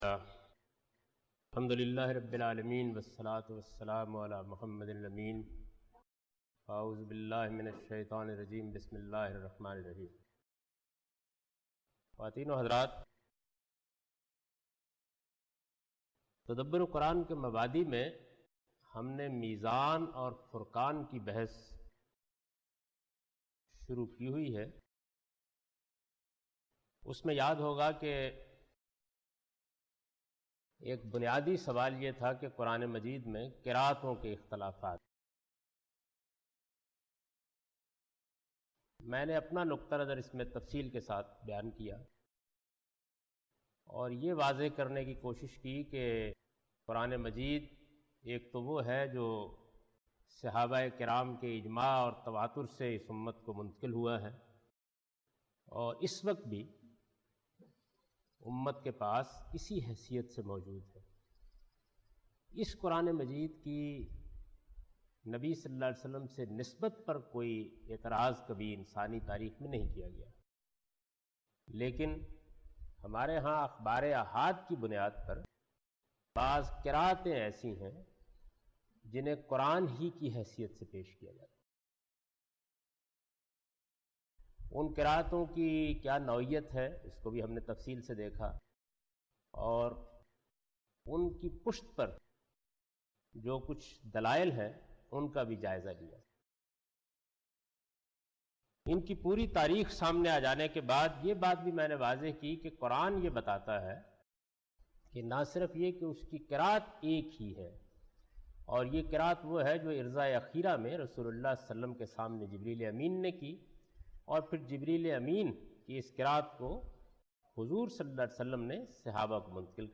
In this lecture he teaches the variant readings of Quran.